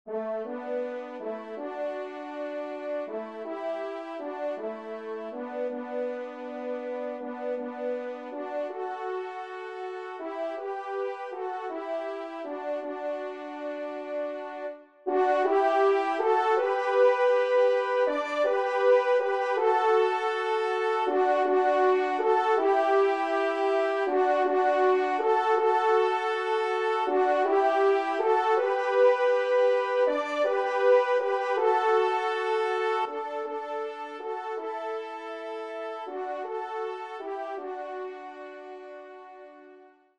2e Trompe